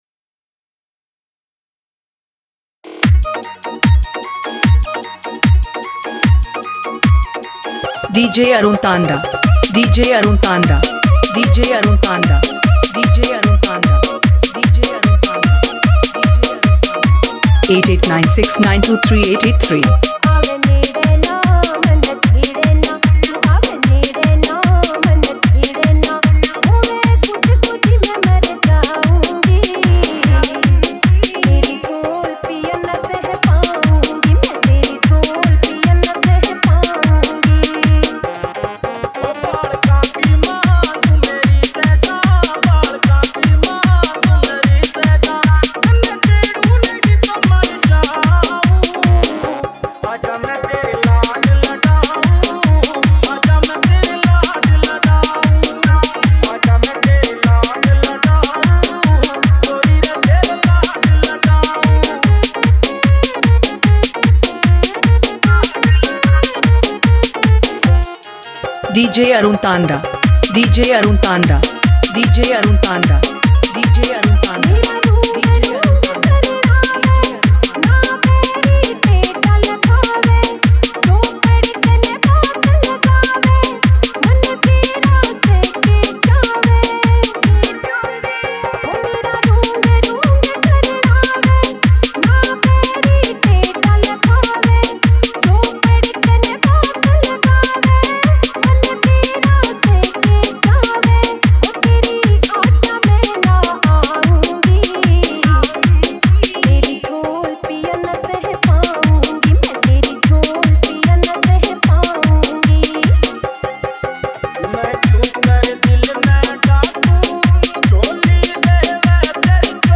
[ DJ Remix Songs ]
» DJ Remix Songs